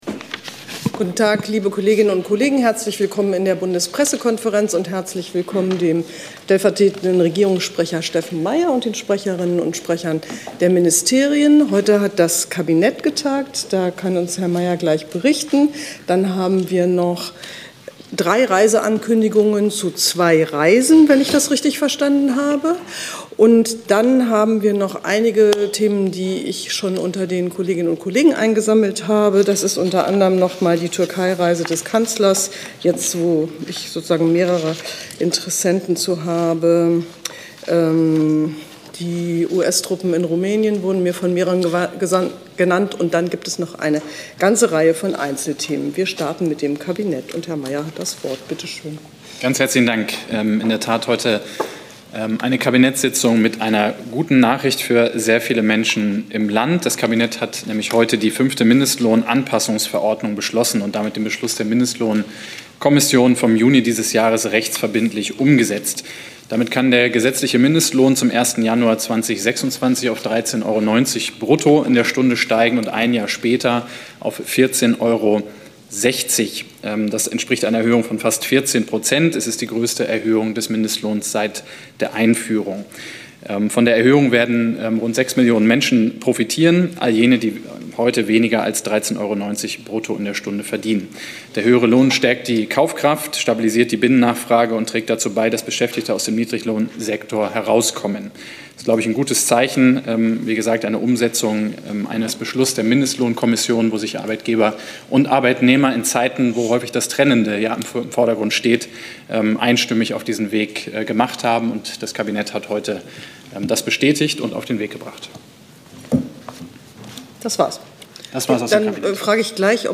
Regierungspressekonferenz in der BPK vom 30. Oktober 2025